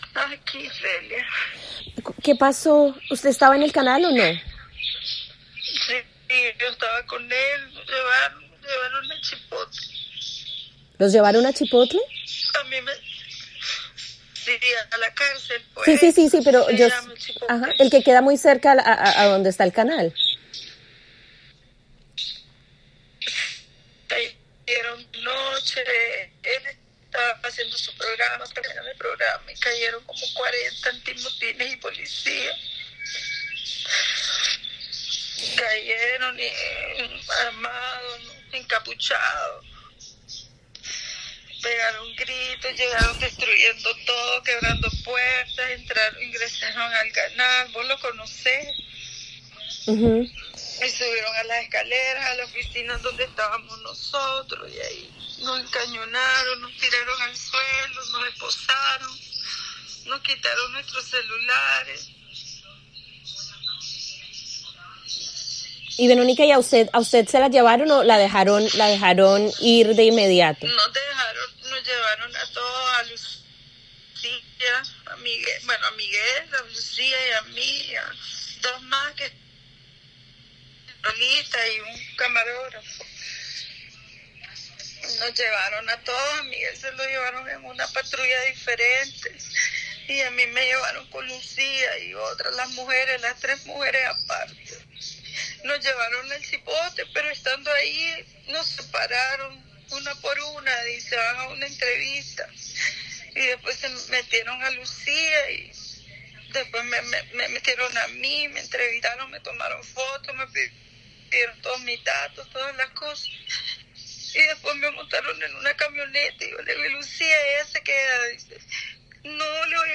Allanamiento a 100% Noticias: Entrevista